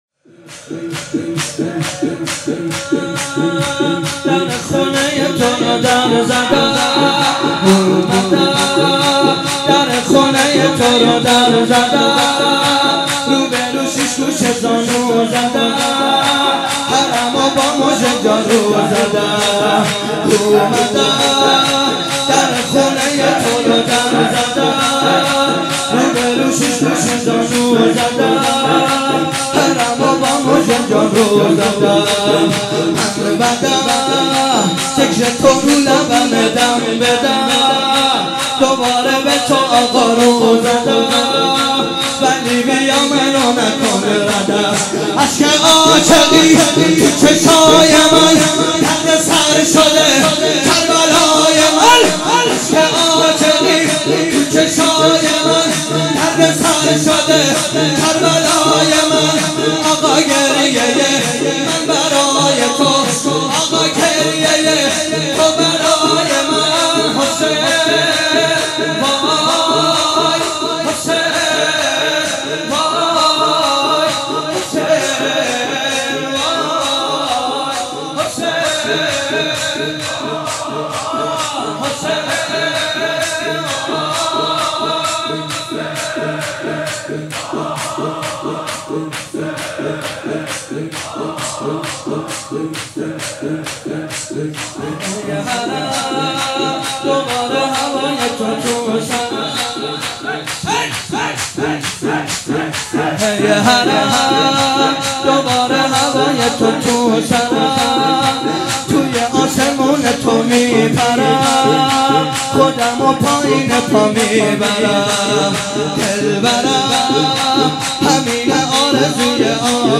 شب سوم رمضان 95، حاح محمدرضا طاهری